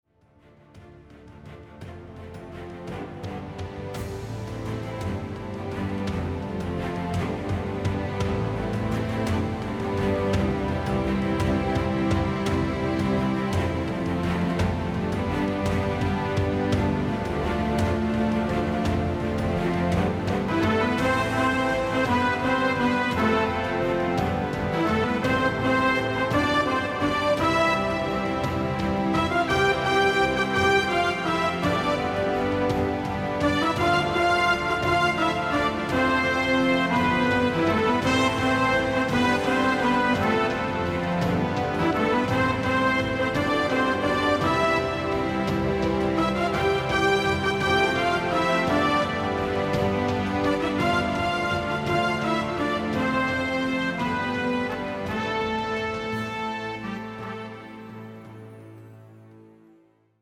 Die Fanfaren ertönen kräftig.
Noch ein paar Streicher und den Schluss machen die Fanfaren.